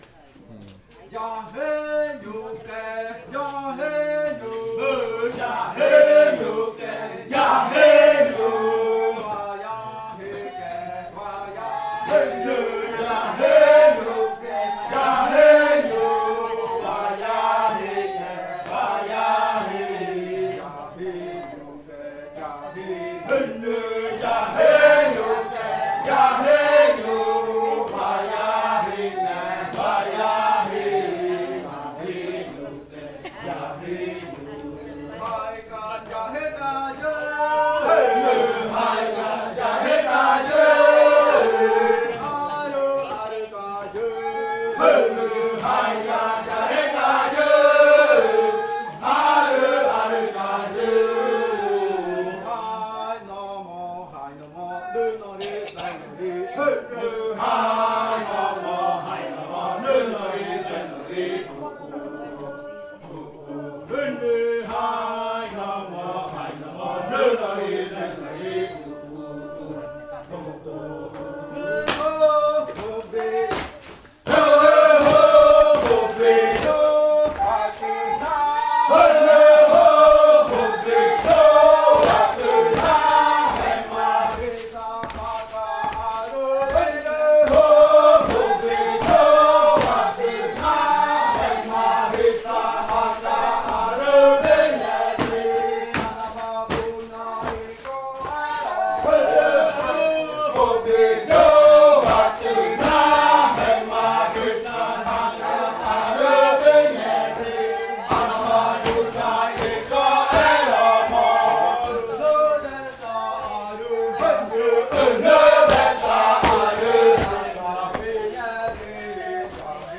Canto de saltar de la variante jaiokɨ
Leticia, Amazonas
con el grupo de cantores bailando en la Casa Hija Eetane.
with the group of singers dancing at Casa Hija Eetane.